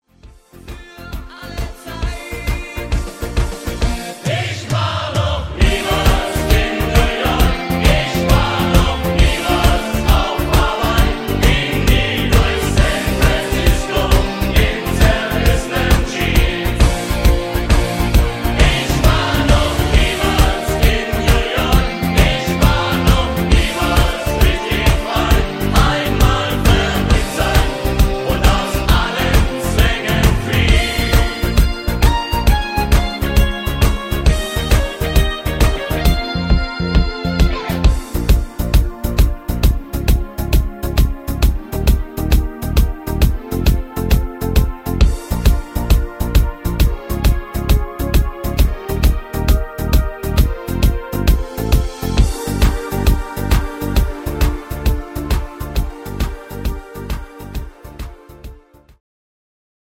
Tanzmix Version